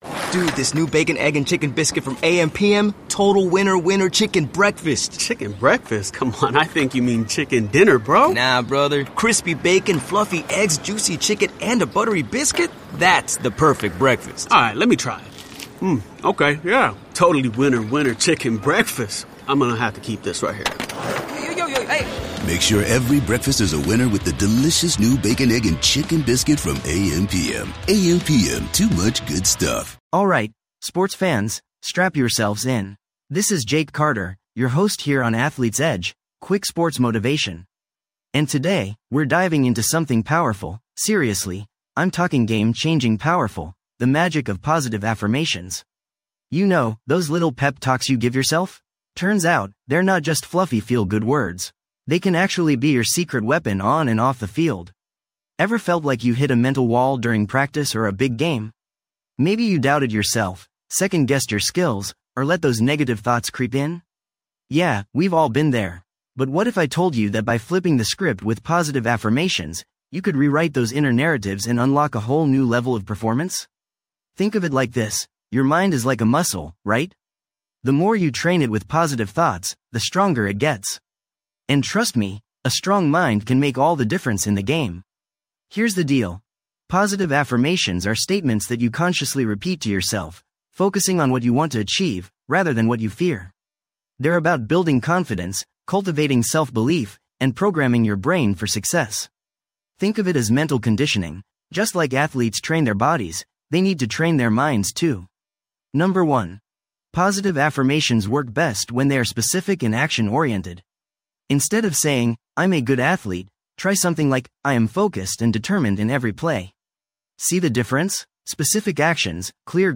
Podcast Category: Sports & Recreation Motivational Talks Self-Improvement
This podcast is created with the help of advanced AI to deliver thoughtful affirmations and positive messages just for you.